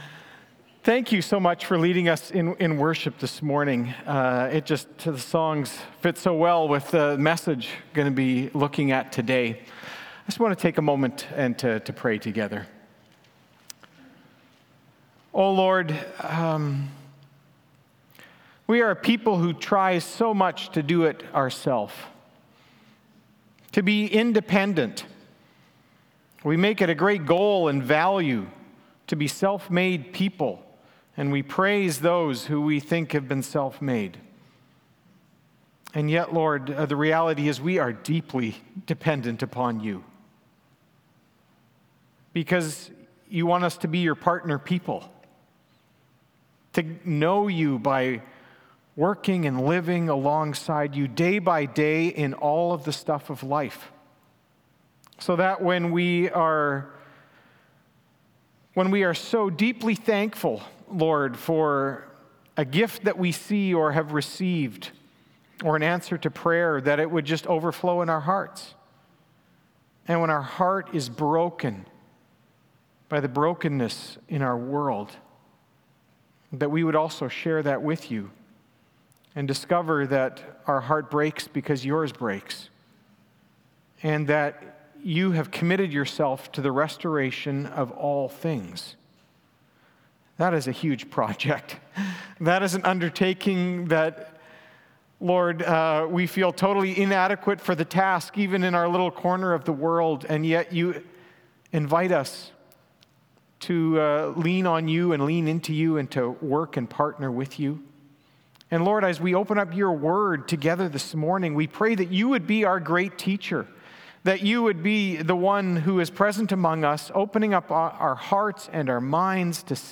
Sermons | Eagle Ridge Bible Fellowship